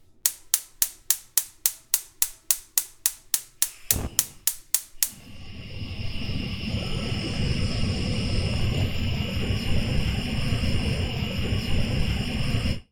На этой странице собраны разнообразные звуки зажигалок: от металлических щелчков до гула пламени.
Шум поджига газовой плиты